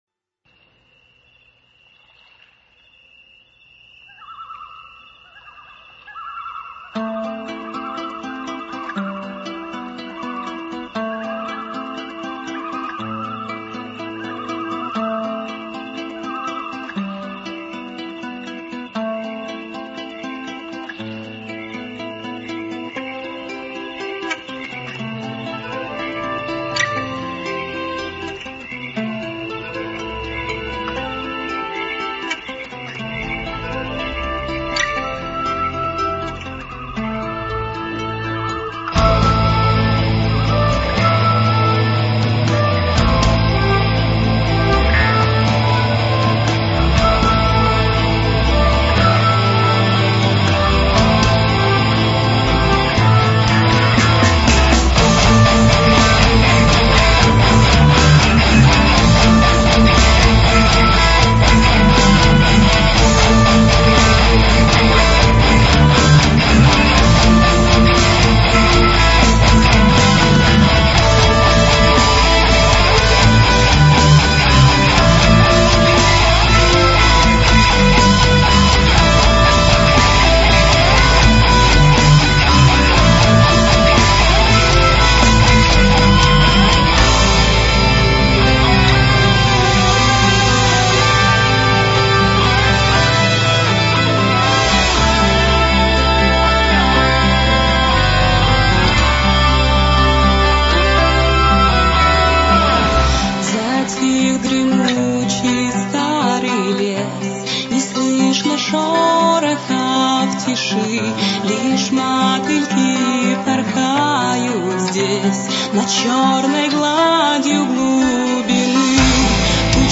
symphonic pagan metal
хорошая песня... плюс отличный женский вокал